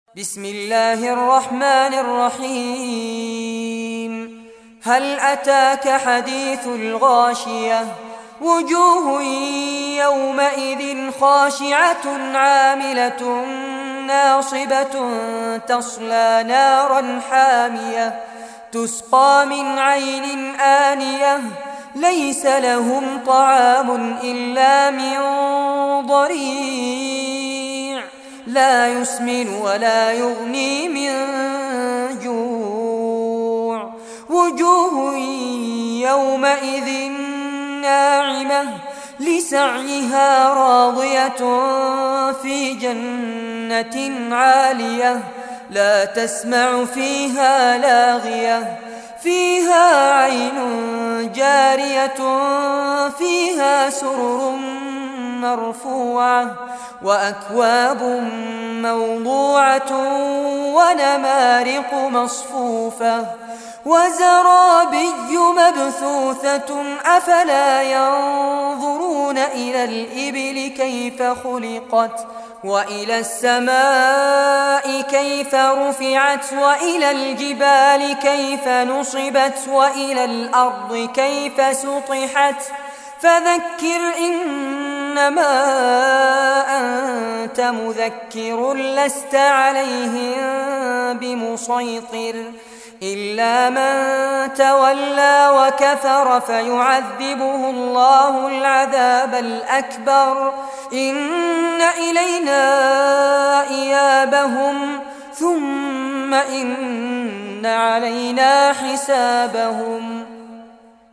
Surah Al-Ghashiyah Recitation by Fares Abbad
Surah Al-Ghashiyah, listen or play online mp3 tilawat / recitation in Arabic in the beautiful voice of Sheikh Fares Abbad.